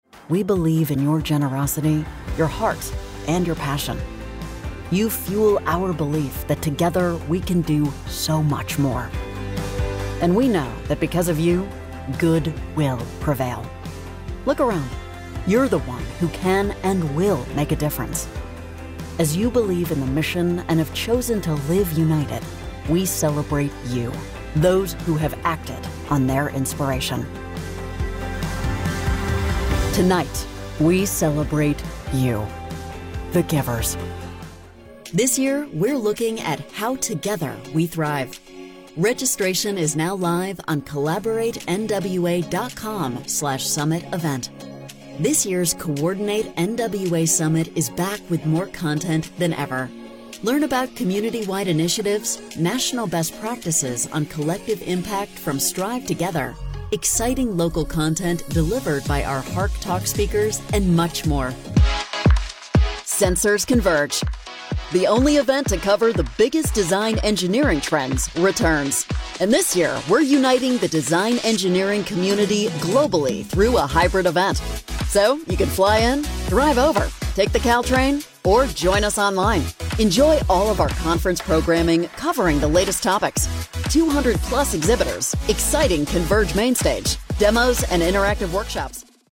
Female
English (North American)
Adult (30-50)
Live/Virtual Event Announcer